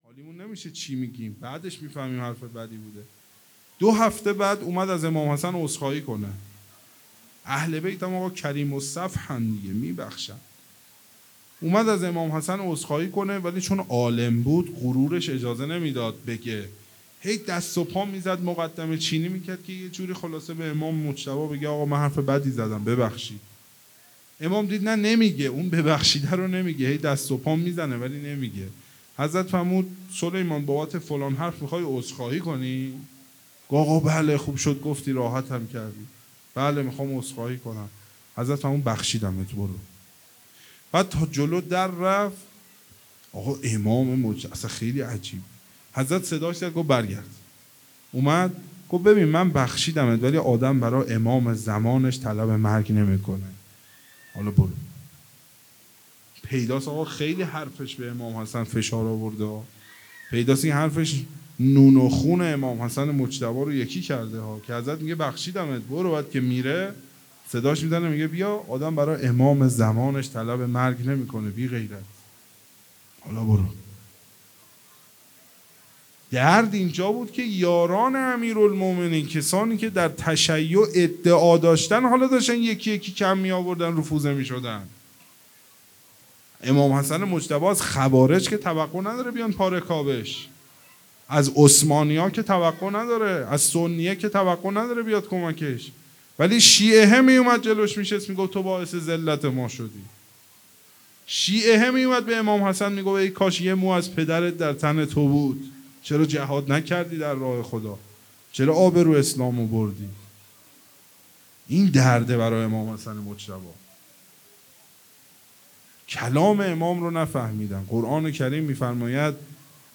هیئت محبان الحسین علیه السلام مسگرآباد